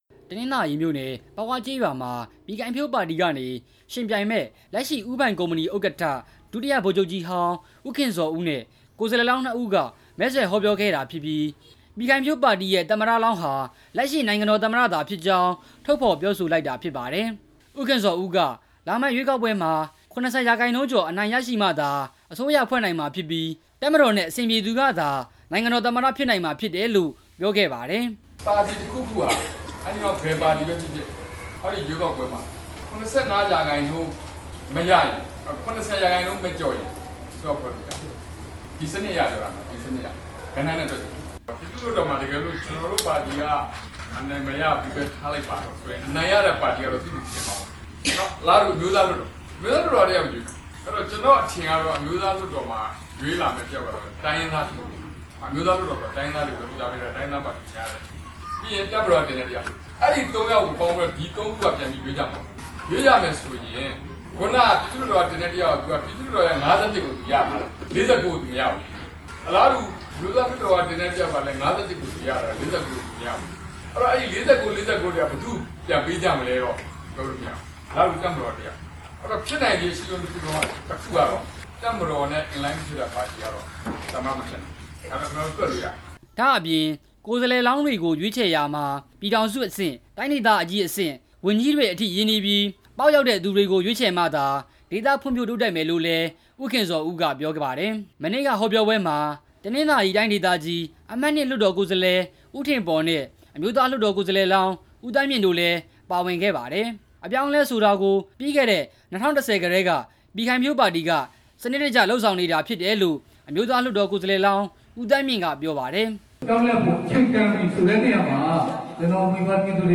ပြည်ခိုင်ဖြိုးပါတီရဲ့ သမ္မတလောင်း နိုင်ငံတော်သမ္မတ ဖြစ်တယ်လို့ ပြောတဲ့အကြောင်း တင်ပြချက်